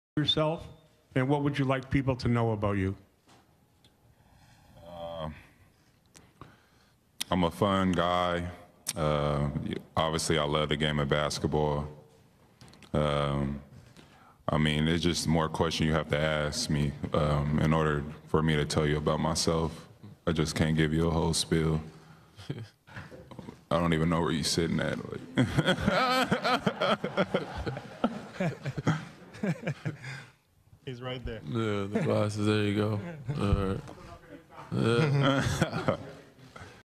Kawhi Leonard laughs at Media Day and says he is a fun guy